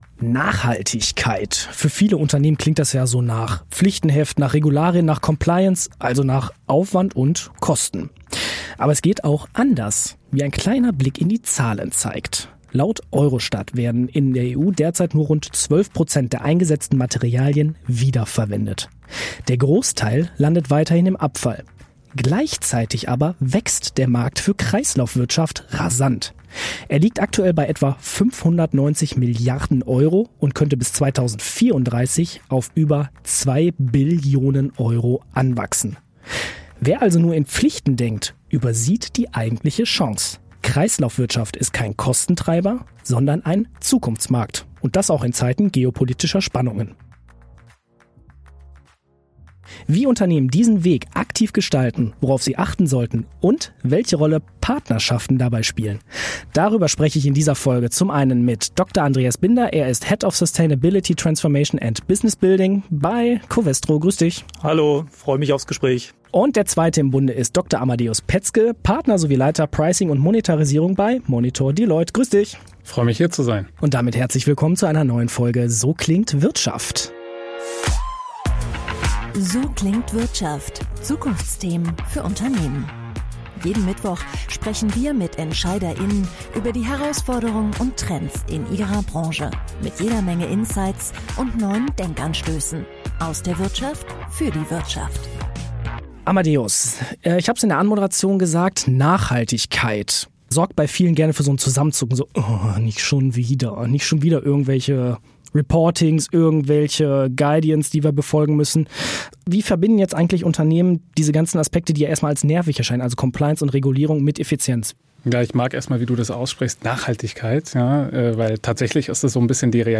Der Business-Talk der Solutions by Handelsblatt Media Group